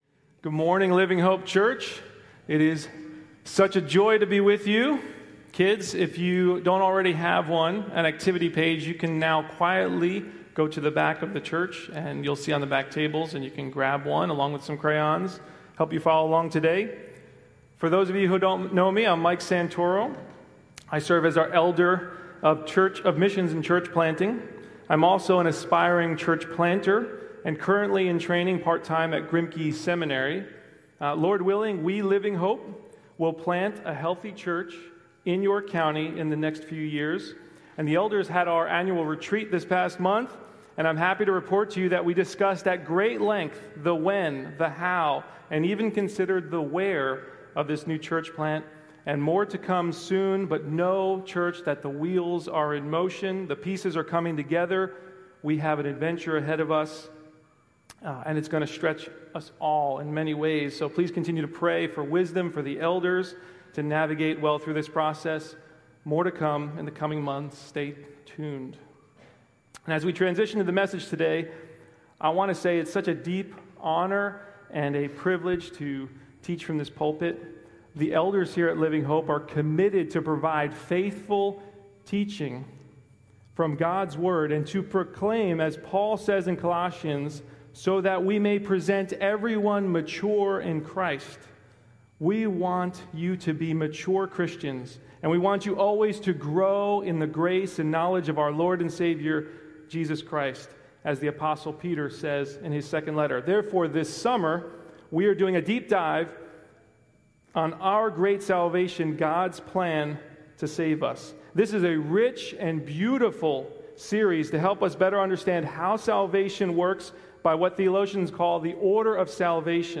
June 29, 2025 Worship Service Order of Service: